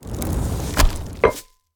The entirety of Geometry Dash's in-game SFX Library!